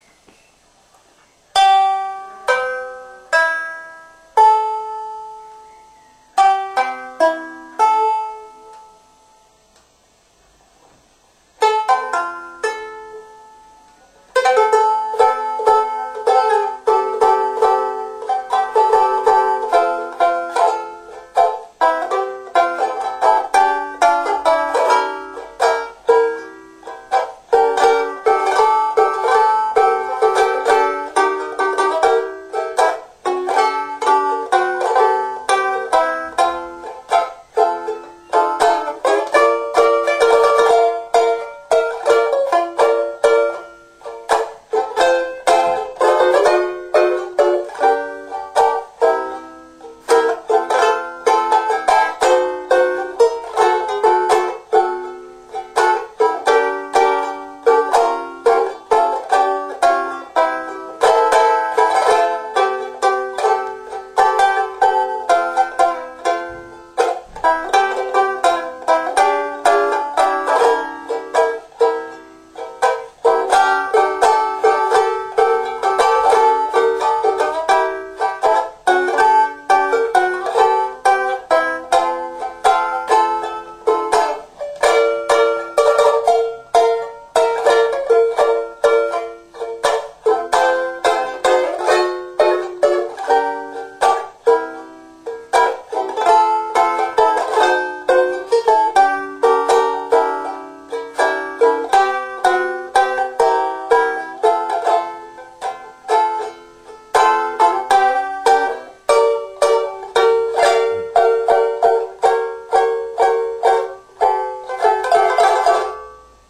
I don’t know anything about the musician other than he appears elderly and plays un Ukulélé MOTU français.